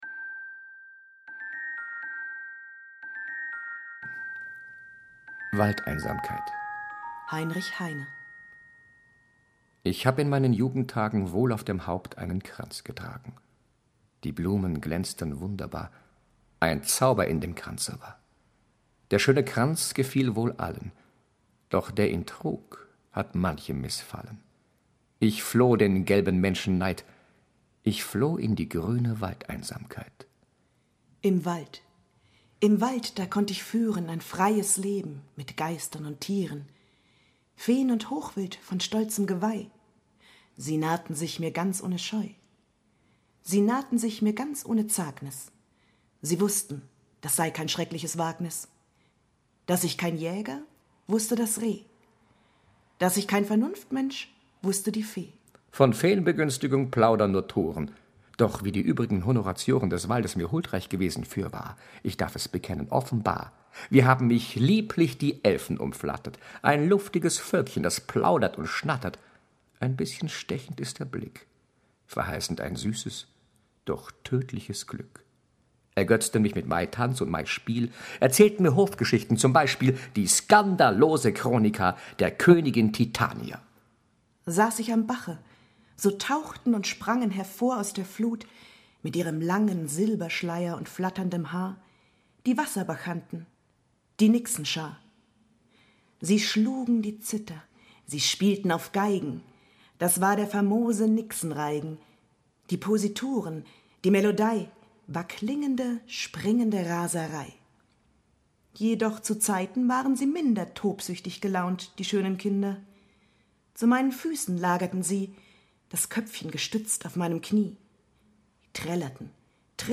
Lyrik